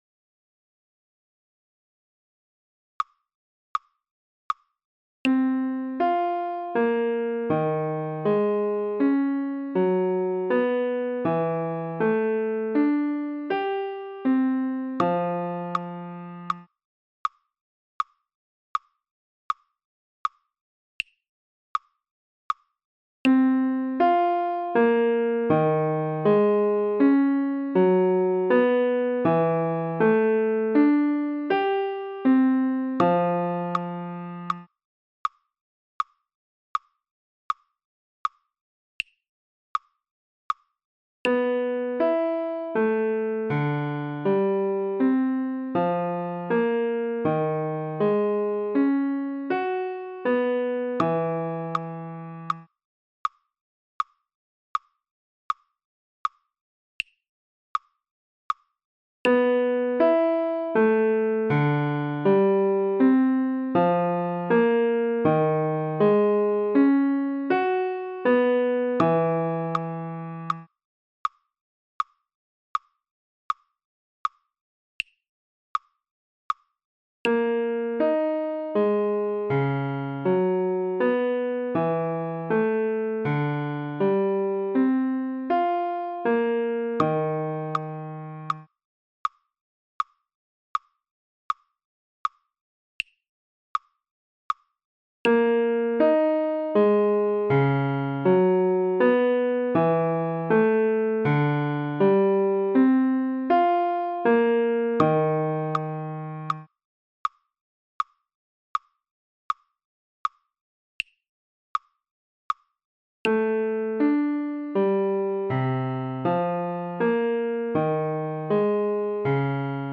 legato-4ths-.mp3